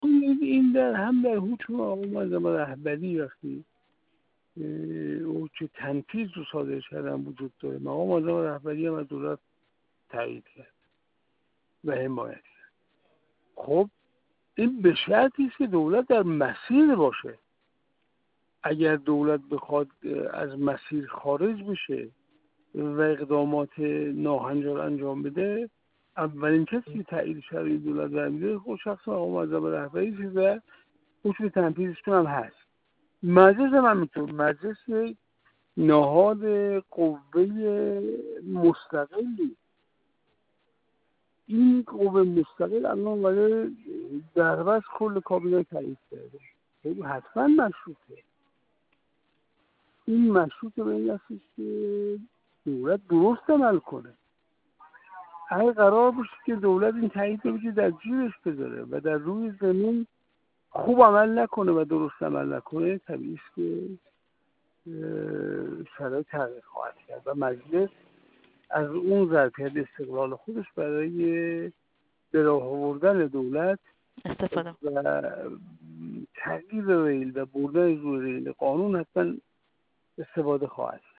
منصور حقیقت‌پور، کارشناس مسائل سیاسی
گفت‌وگو